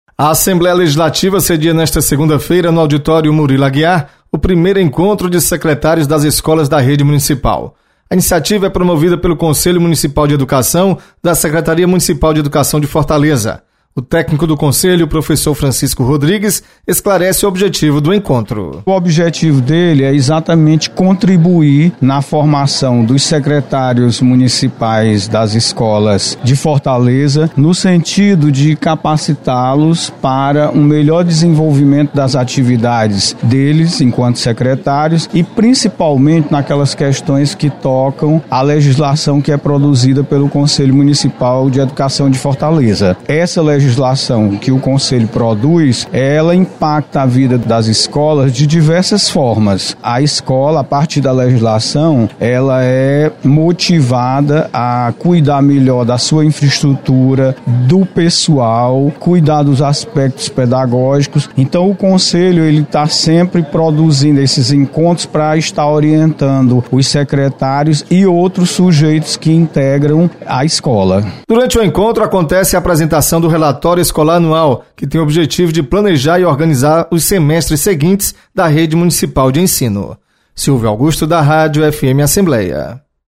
Secretaria de Educação do Município realiza, na Assembleia Legislativa, encontro para debater planejamento escolar. Repórter